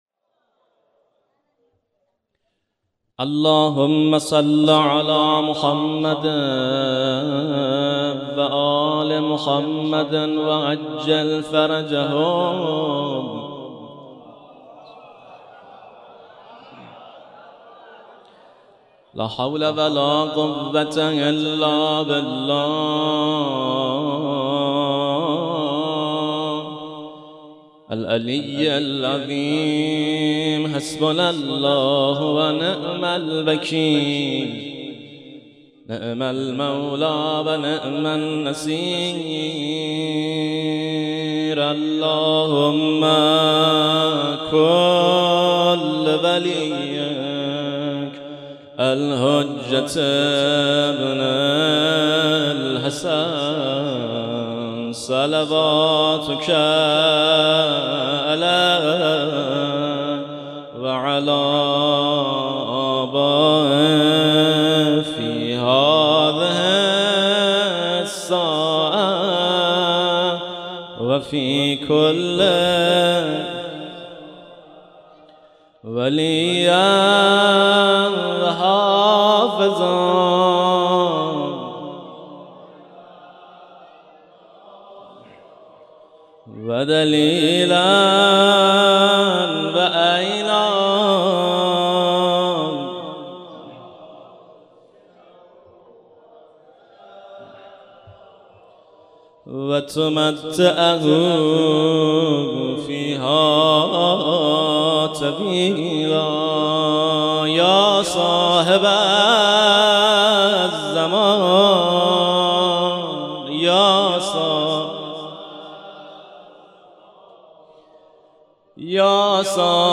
مداحی یادواره شهدای استان مازندران محرم97